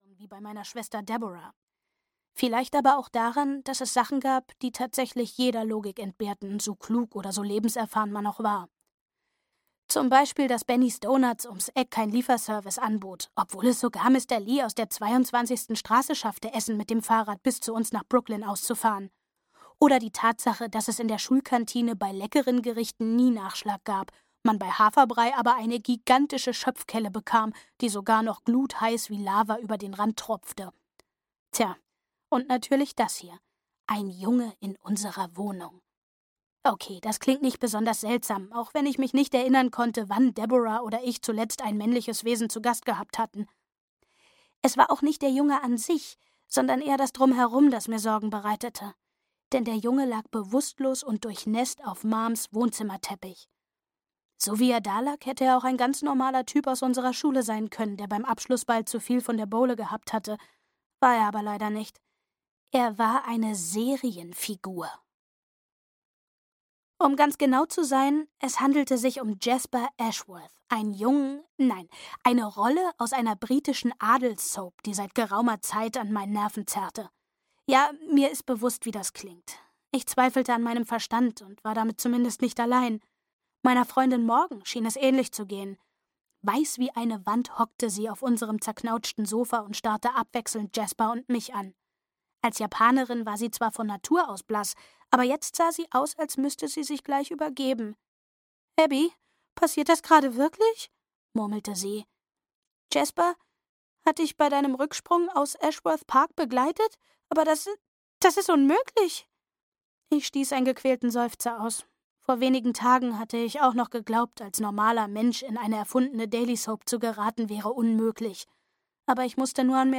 Verliebt in Serie 2: Lilien & Luftschlösser. Verliebt in Serie, Folge 2 - Sonja Kaiblinger - Hörbuch